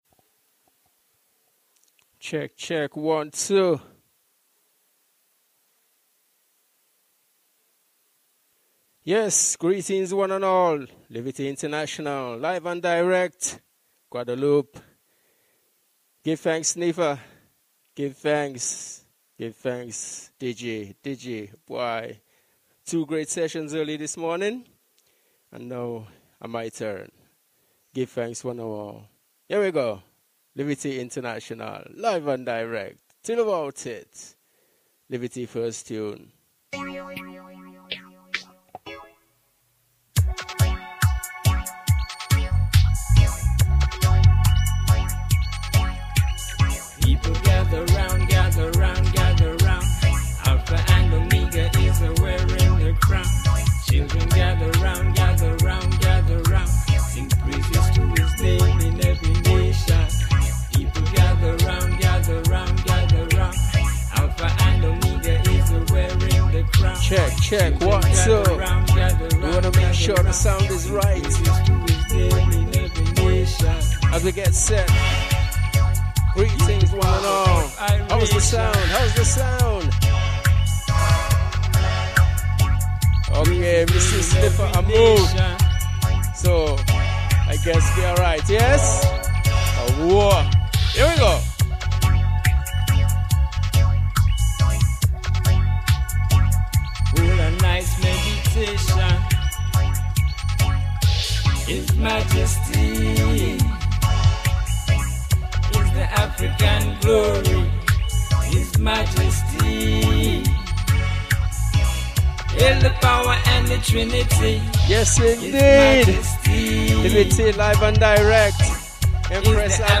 roots and dub